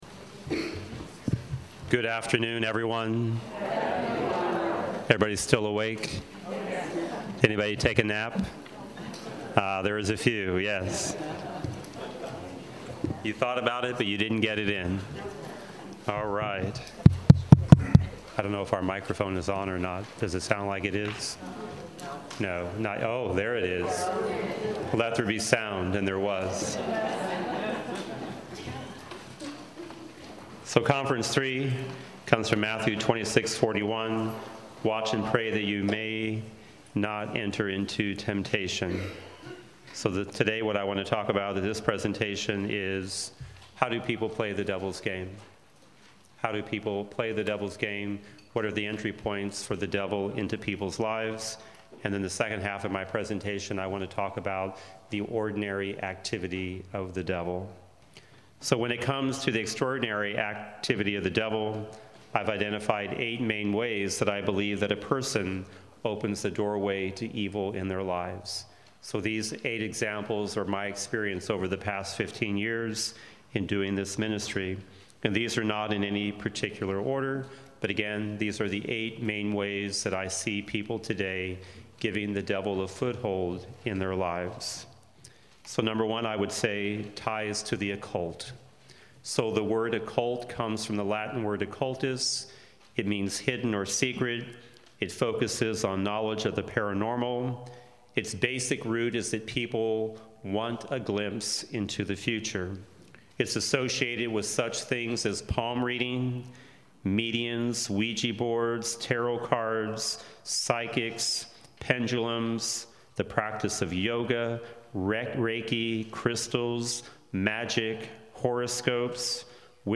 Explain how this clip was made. gives his third talk of five at our conference on exorcism in January 2020